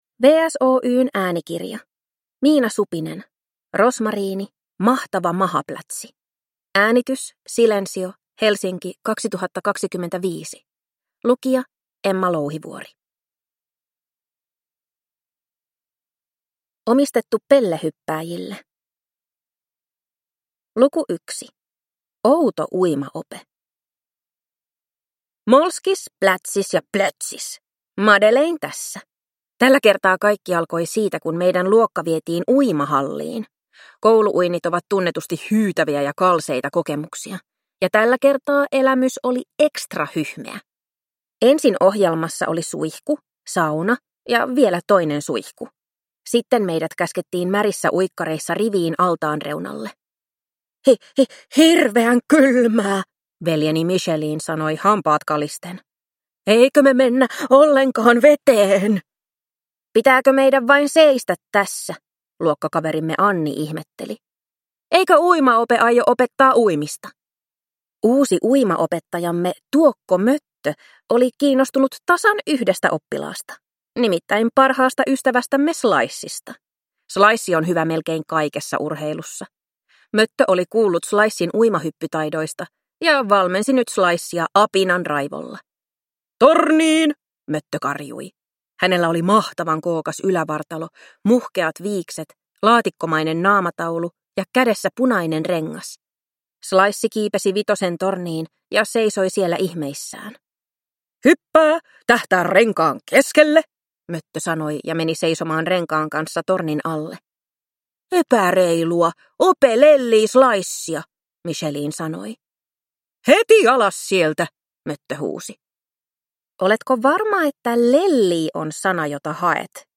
Rosmariini: Mahtava mahaplätsi – Ljudbok